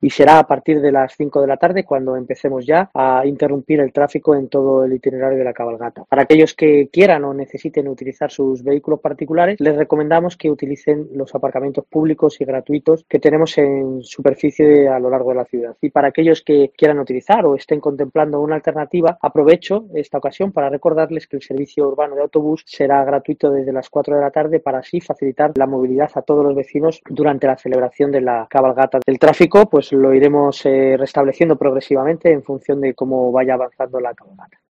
El concejal Angel Molina informa que habrá cortes de tráfico y buses urbanos gratuitos desde las 16h